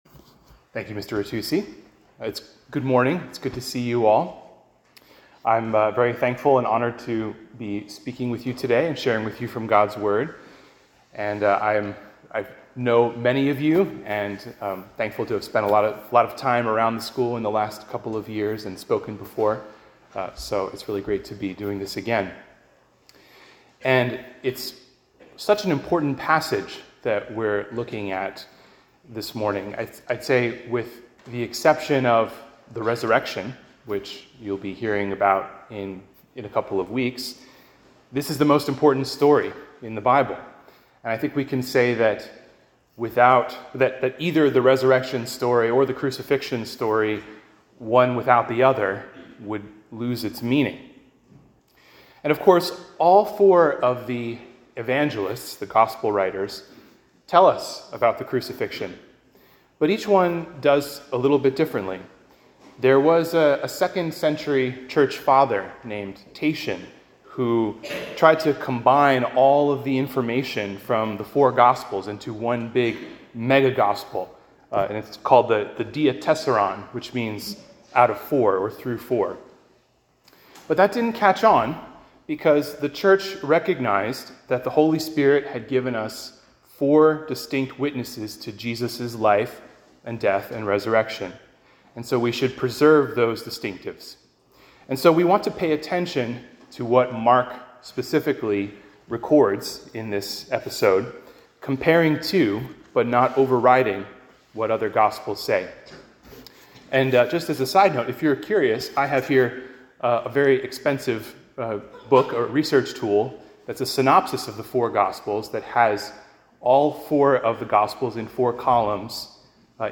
Sermon: Why Have You Abandoned Me?